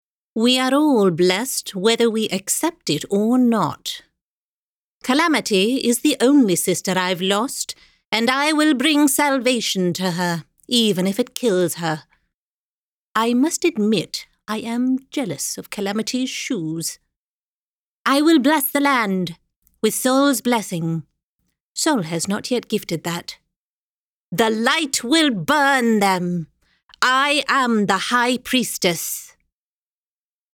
Sprechprobe: Sonstiges (Muttersprache):
Accents: British, North American, Indian, French, Australian, South African